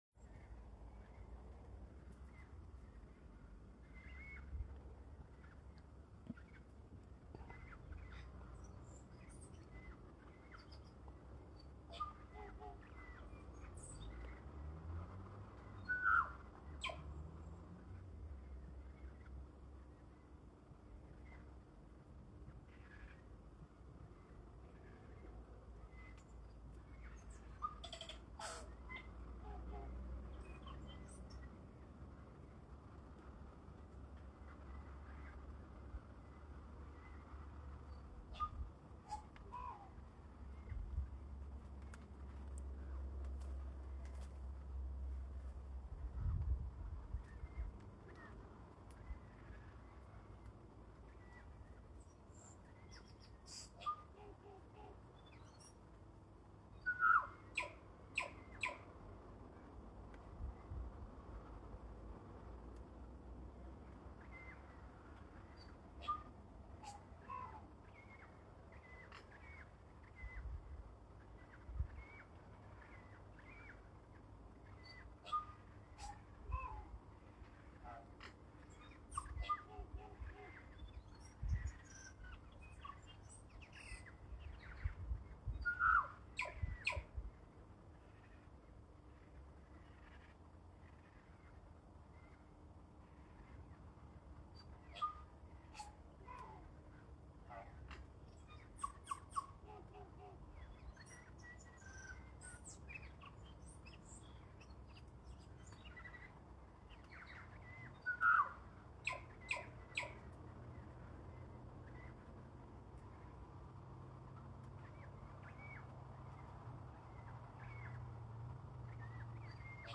bird.mp3